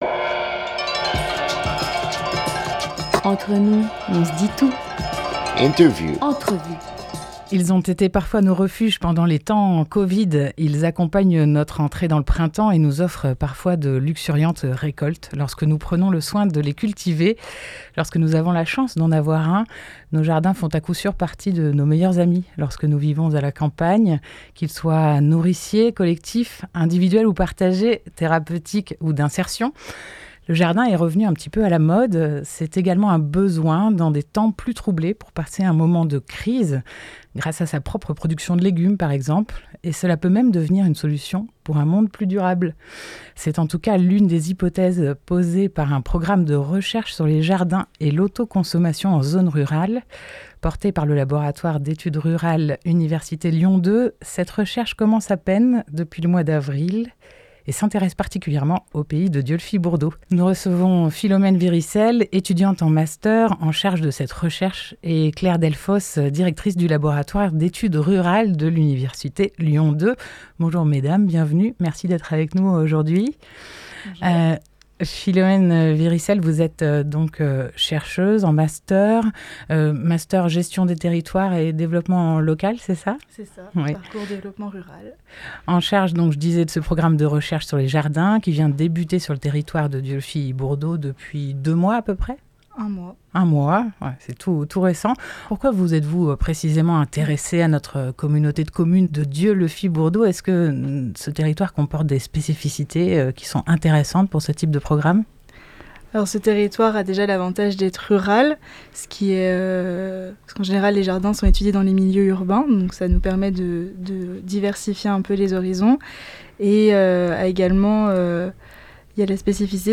13 juillet 2021 8:04 | Interview